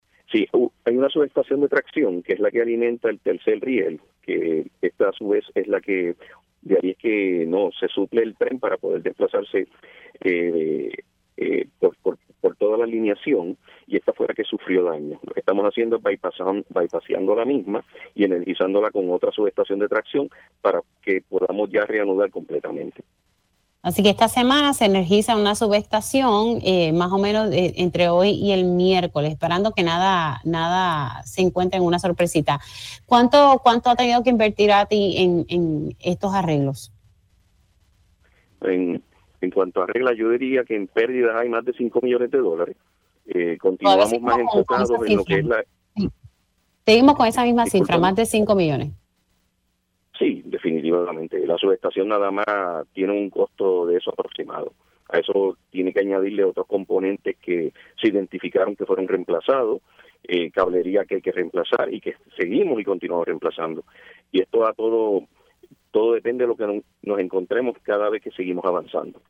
El director de Autoridad de Transporte Integrado (ATI), Josué Menéndez informó en Pega’os en la Mañana que los gastos en trabajos de arreglo de la subestación del Tren Urbano, que sufrió un fuego el pasado 14 de mayo, han superado los cinco millones de dólares.